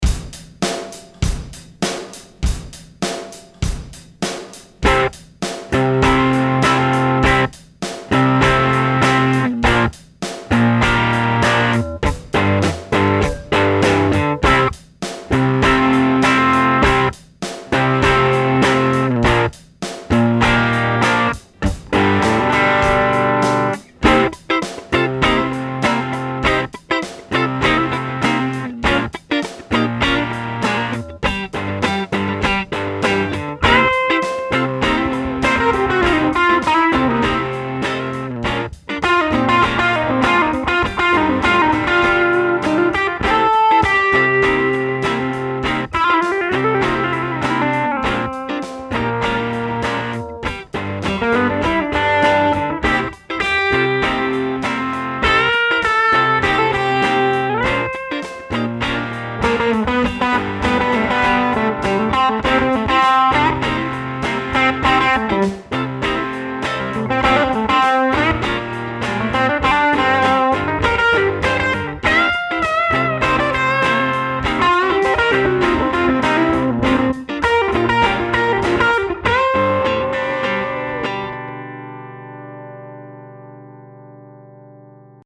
Der Tweed 5E5A ist für mich wie ein größerer 5E3 – das ist natürlich subjektiv, aber er klingt einfach so: voller, kräftiger und mit etwas mehr Headroom, bleibt dabei aber erstaunlich handlich. Sein 15"-Speaker liefert einen warmen, runden Ton mit viel Fundament und einem lebendigen Mittenbereich, der sich im Bandmix hervorragend durchsetzt. Er reagiert direkt auf das Spiel und bleibt dabei offen, dynamisch und typisch Tweed – rau, musikalisch und einfach inspirierend.